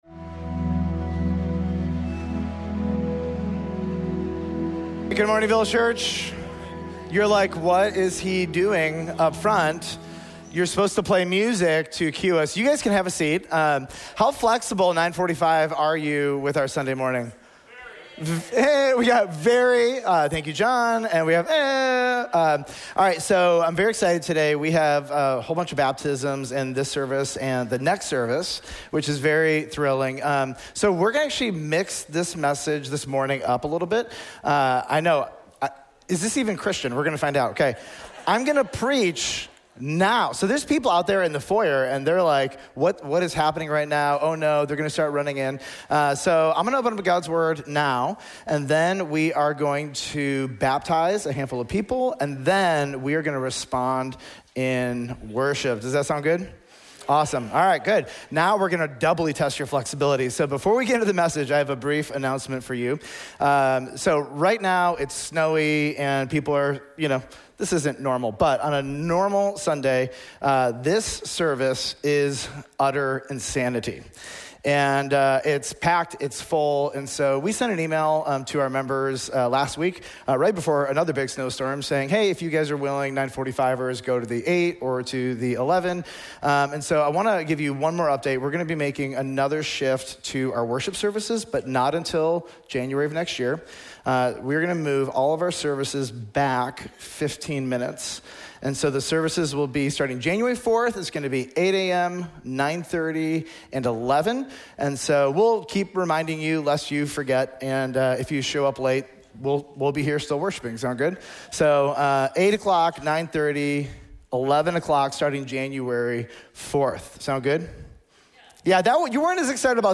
Village Church of Bartlett: Sermons